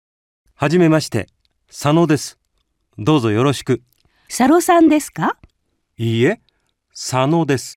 I. 聴解 (Nghe hiểu)